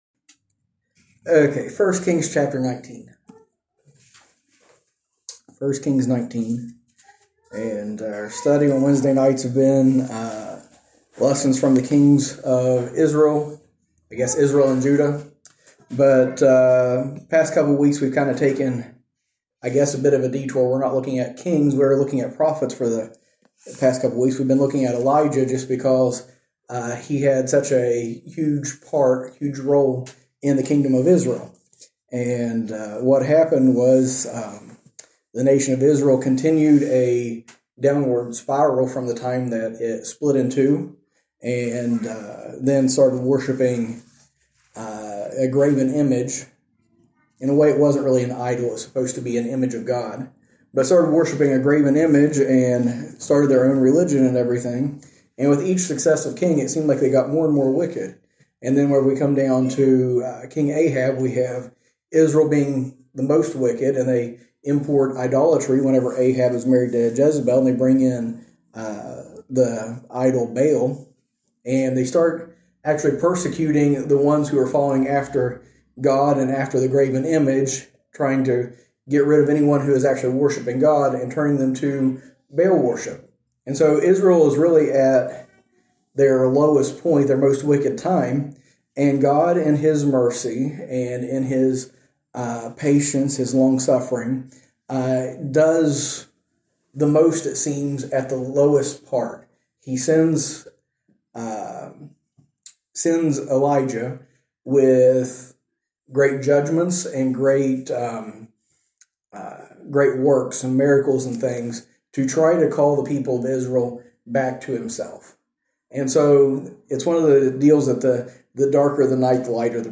A message from the series "Recent Sermons."